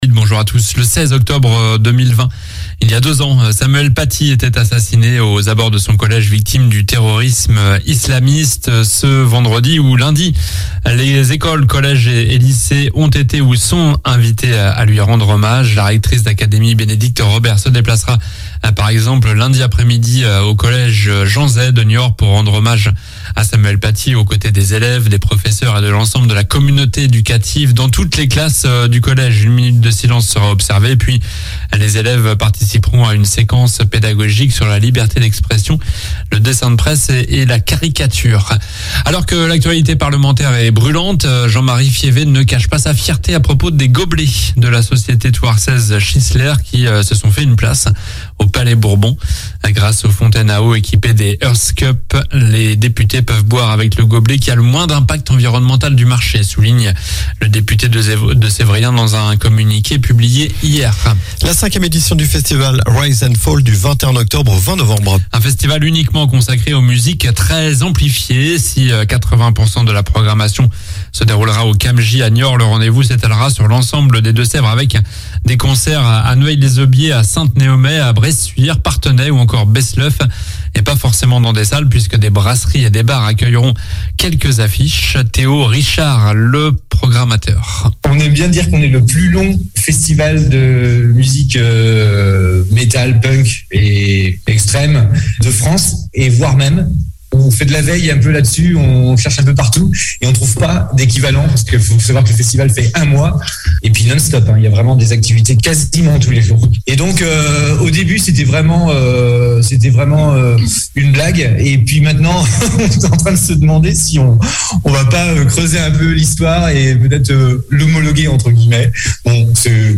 Journal du samedi 15 octobre (matin)